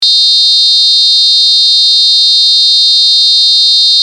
SIRENA 8 TONOS
Sirena con 8 tonos diferentes
Tono_4